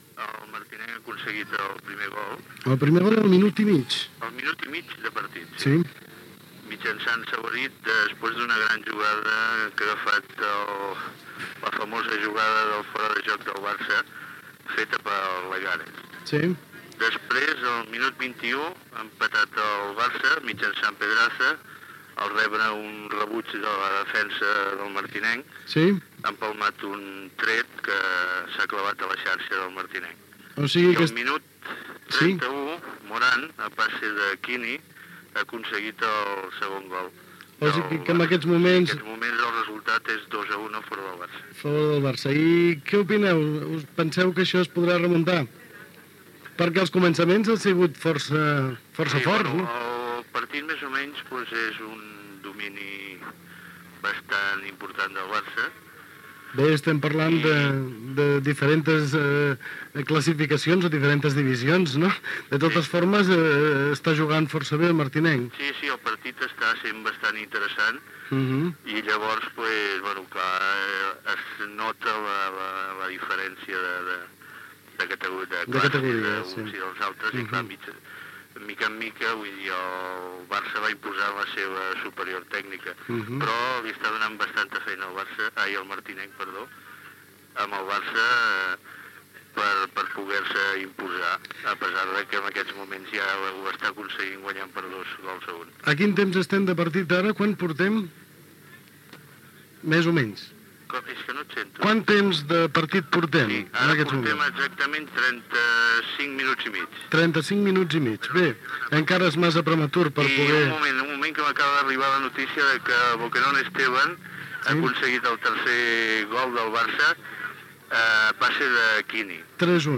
Connexió amb el camp del Futbol Club Martinenc on se celebra els partit per commemorar els 75 anys d’història del club.